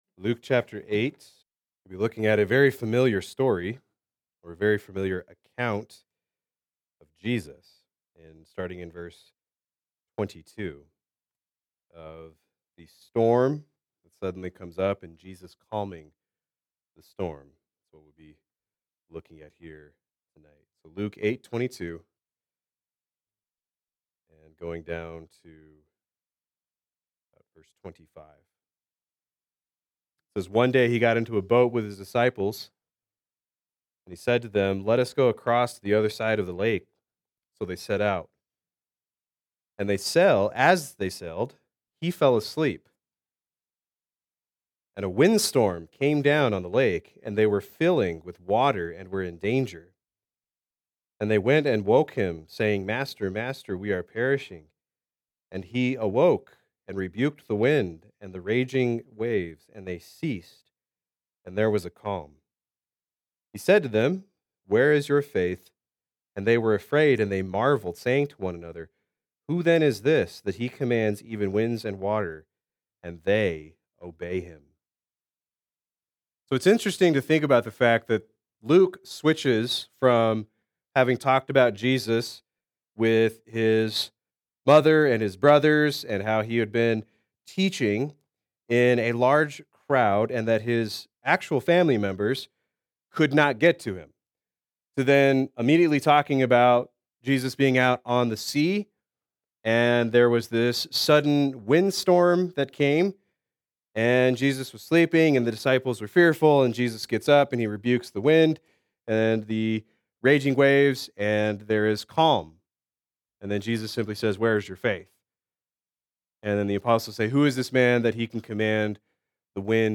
Play Sermon Get HCF Teaching Automatically.